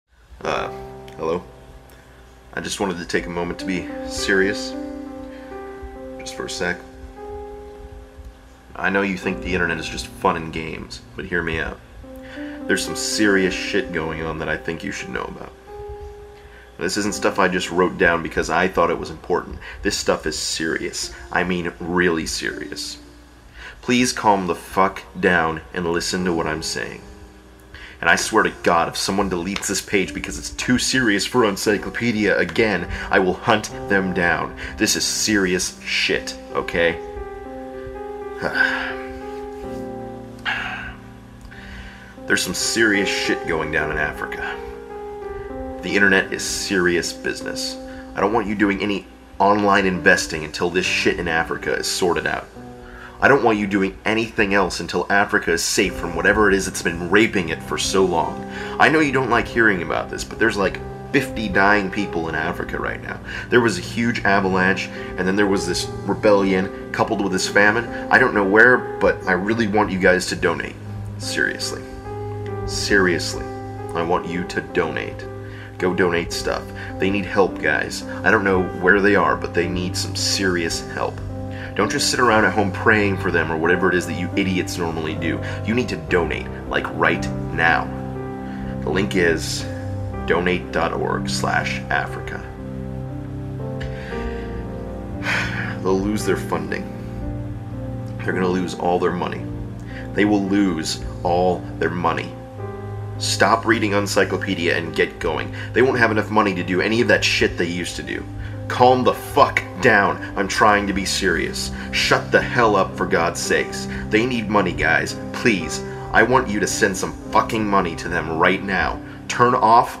Combining File:Serious One.mp3 and File:Serious Two.mp3 into one file, with sad muzak for ambience.